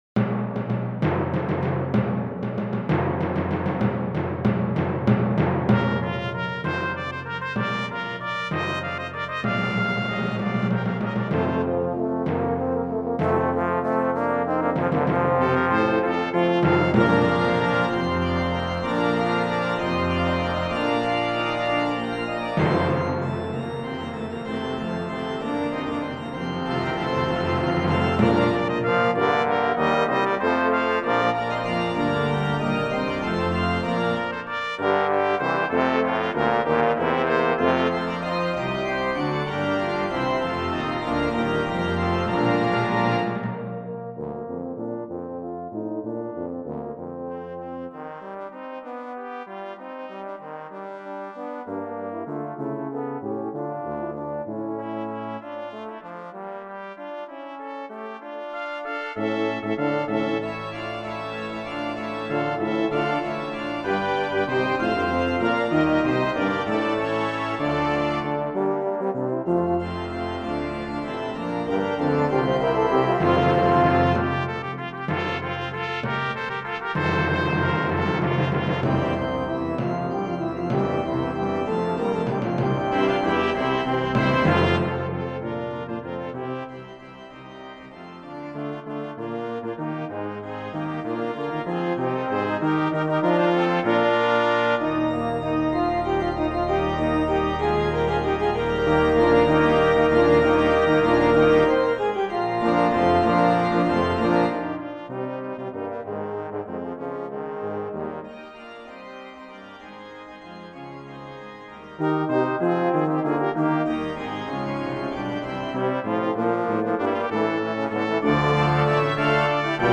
Voicing: String Quartet